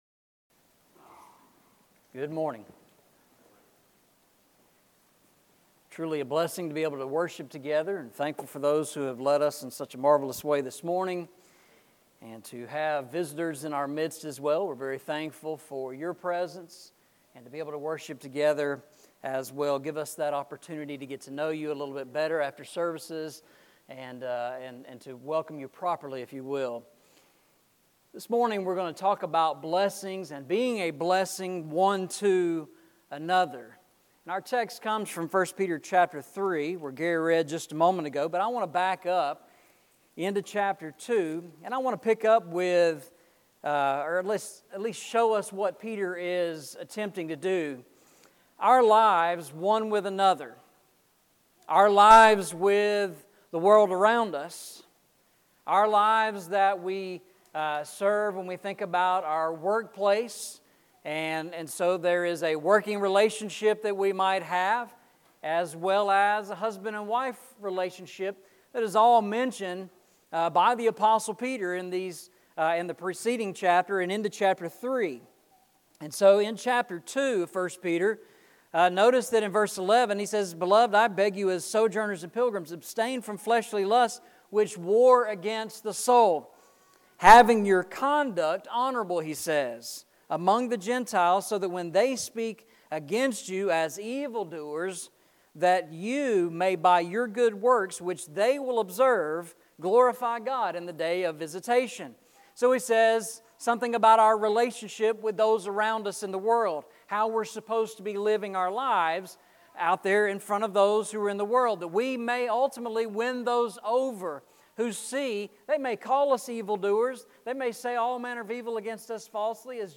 Eastside Sermons Passage: 1 Peter 3:8-12 Service Type: Sunday Morning « How Shall They Hear Without a Preacher?